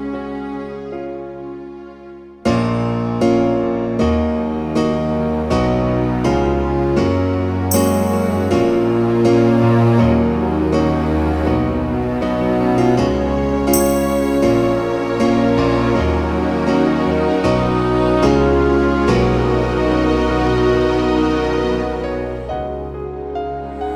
Album Version For Duet Rock 6:01 Buy £1.50